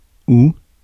Ääntäminen
France (Paris): IPA: /u/